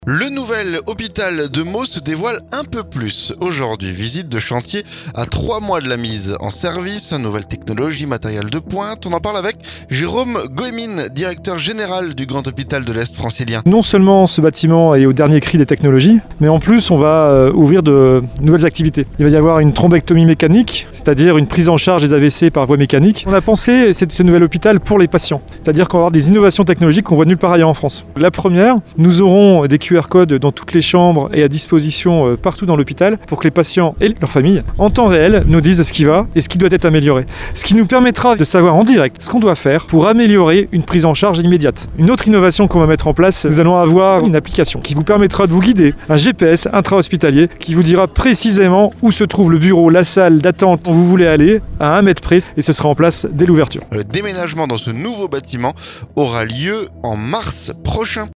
MEAUX - Le futur bâtiment de l'hôpital se dévoile.. Reportage
Le Nouvel hôpital de Meaux se dévoile un peu plus aujourd’hui. Visite de chantier à 3 mois de la mise service.